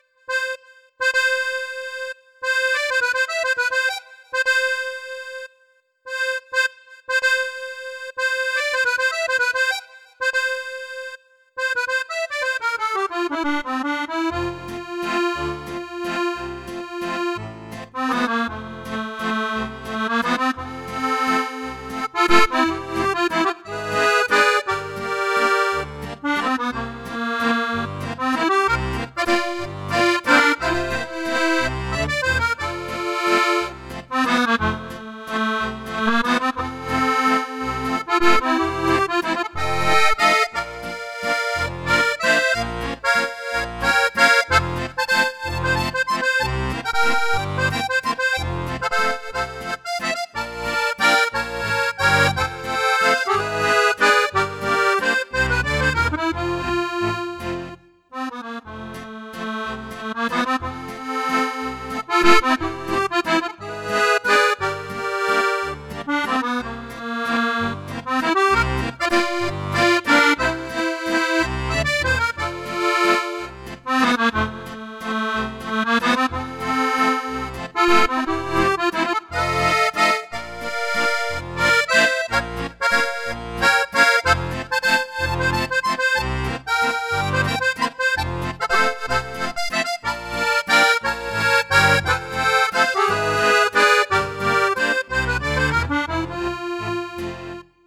Nun habe ich aber zufällig eine kleine Kostprobe von einem Walzer den ich seit gestern versuche in mein verschrumpeltes Hirn rein zu prügeln.
Hier eine kleine Kontrollaufnahme - ich weiß. klingt schlimm.